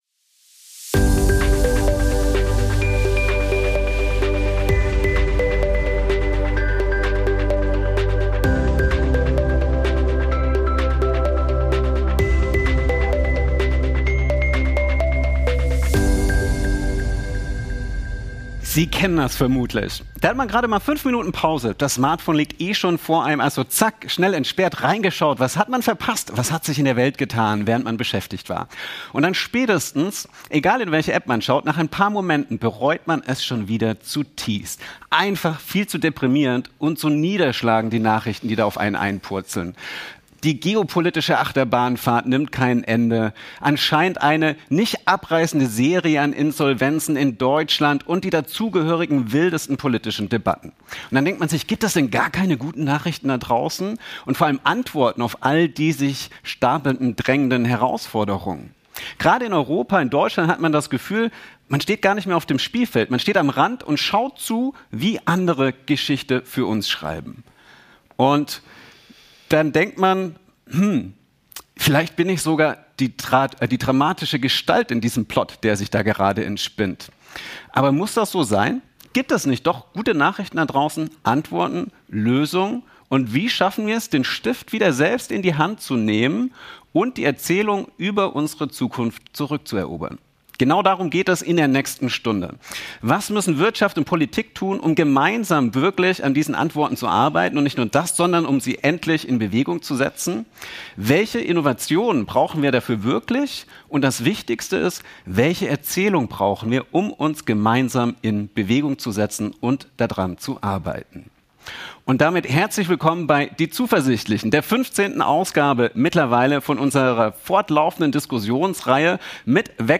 Im Talk der Zuversichtlichen